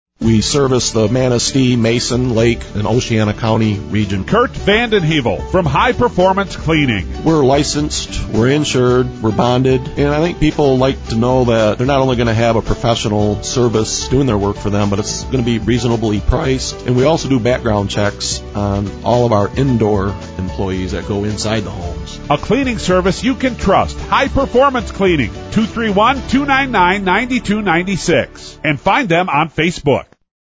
Radio Commercials